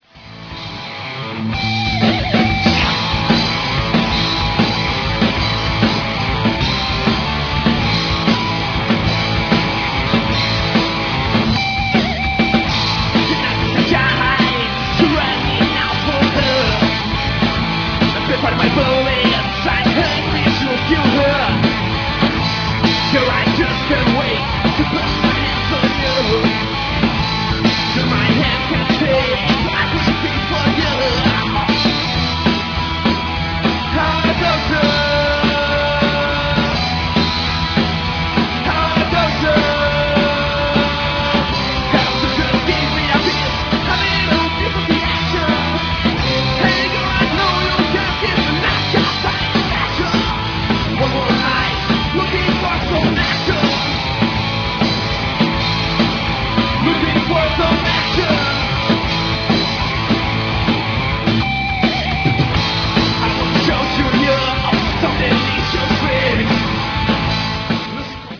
Third World Glam Rock Band